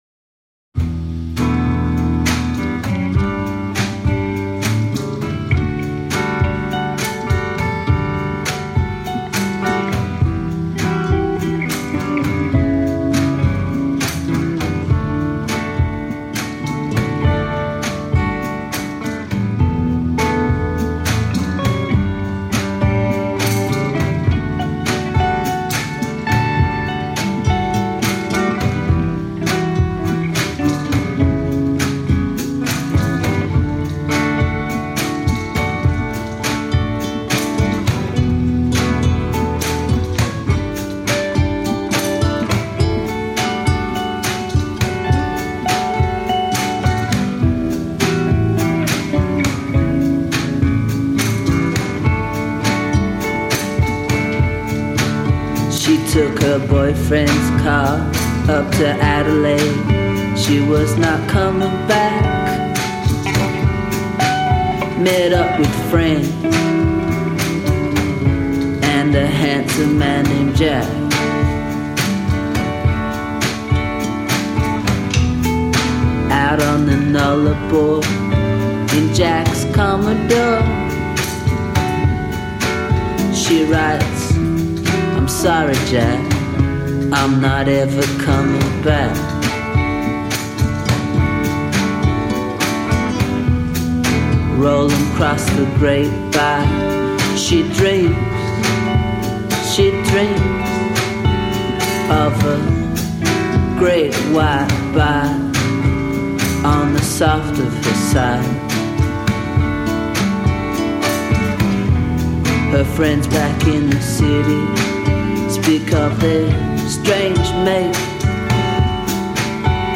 Relaxed wonderfulness, from Australia